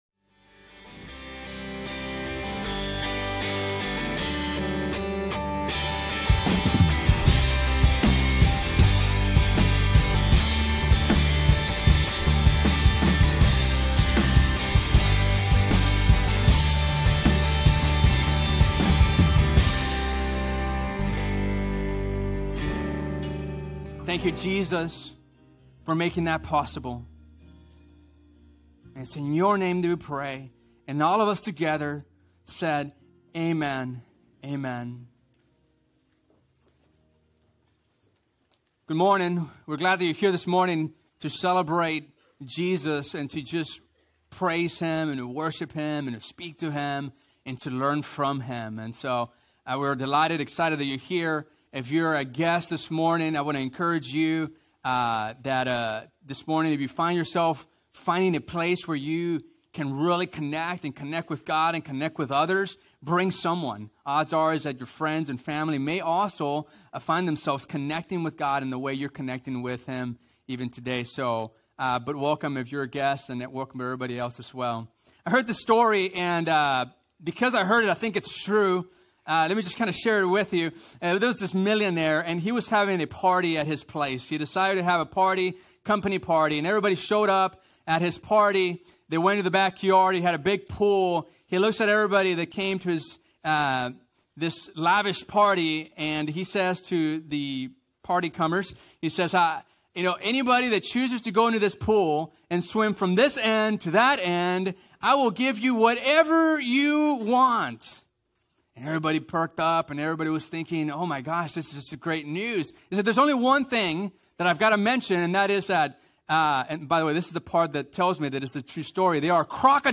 Two Rivers Bible Church - Sermons